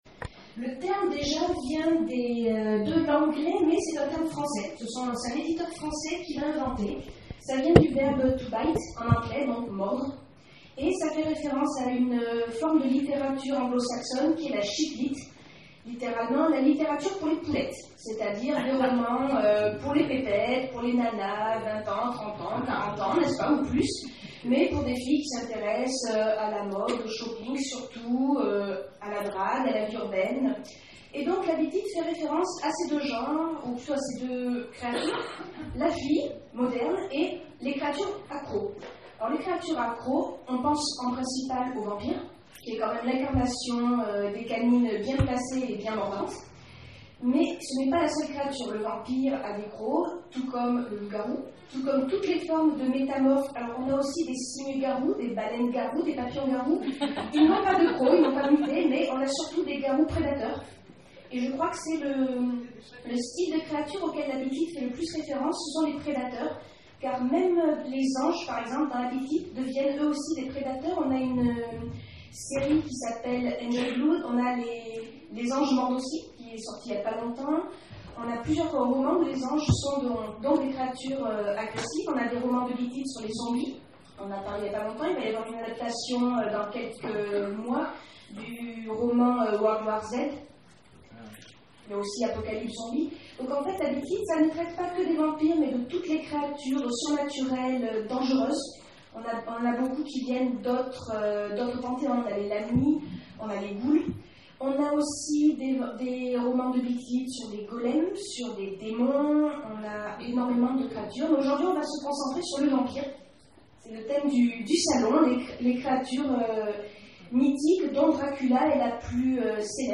Salon du roman populaire d'Elven
Conférence